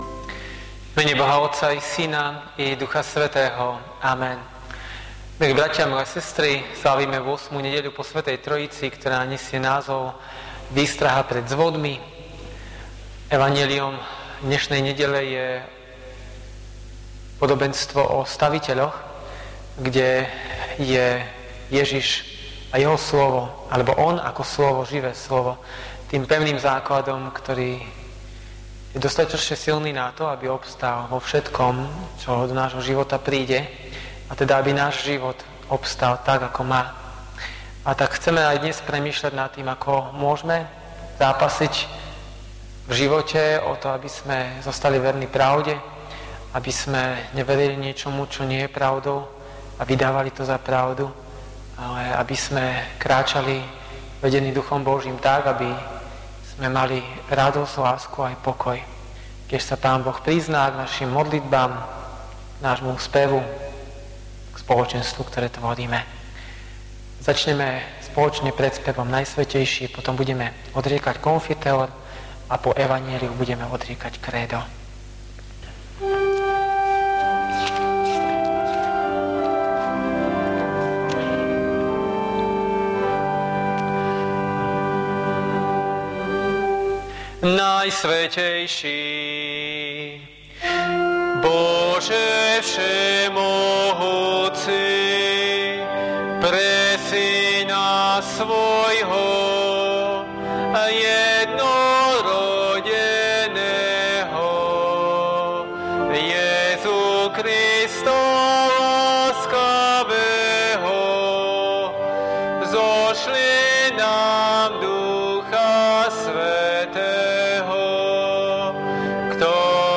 V nasledovnom článku si môžete vypočuť zvukový záznam zo služieb Božích – 8. nedeľa po Sv. Trojici.